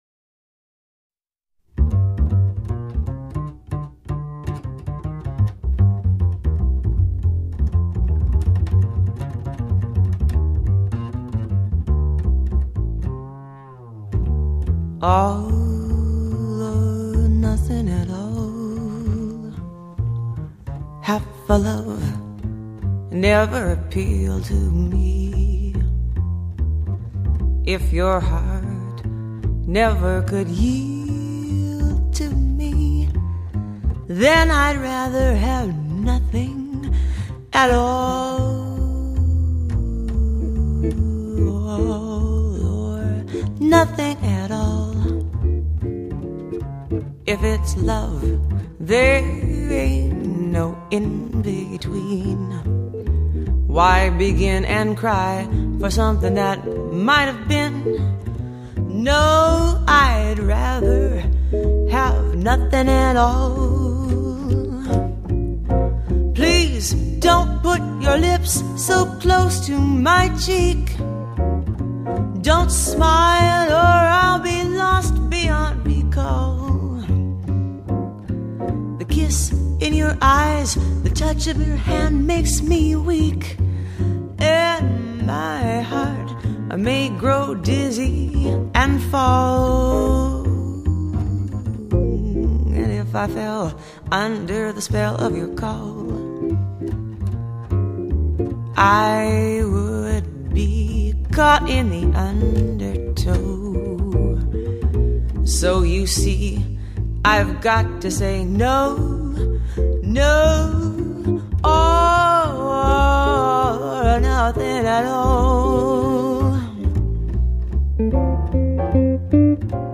音乐类型：爵士乐